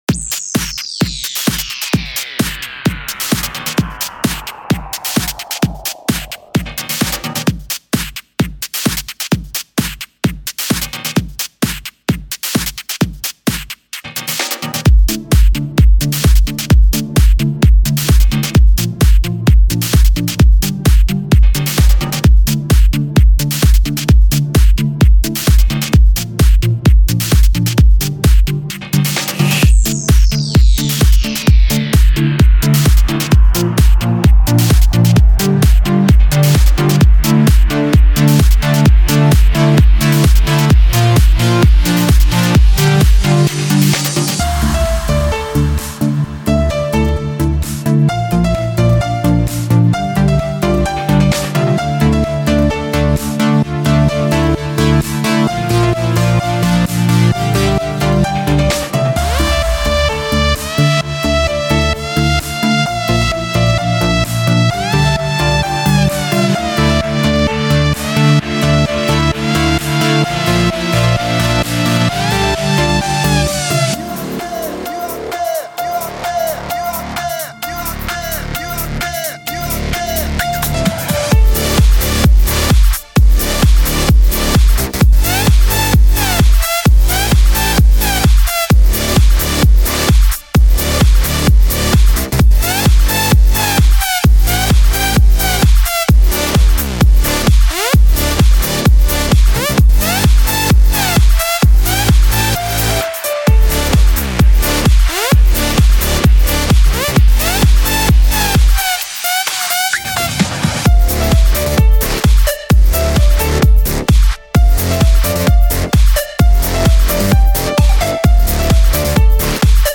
лучшая клубная электро хаус mp3 музыка 2011
Жанр: House - Electro